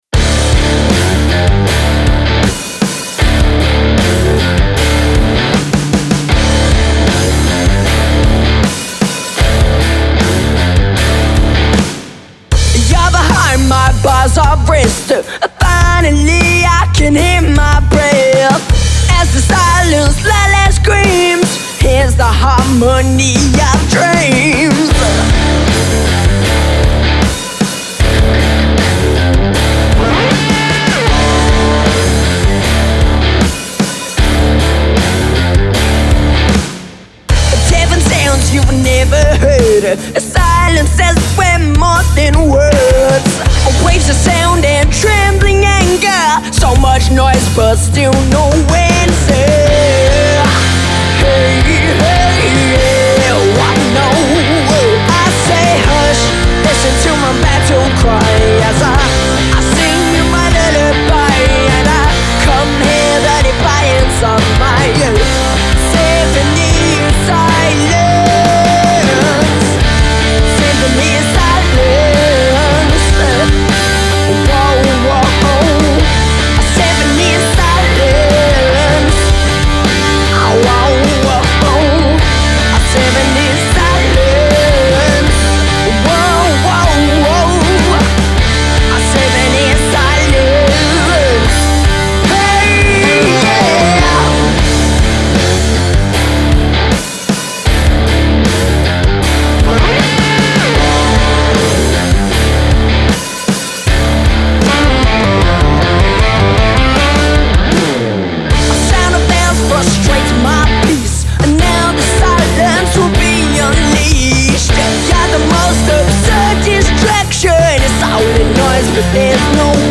-3,6 LUFS
finde, dafür gehts noch mit der verzerrung.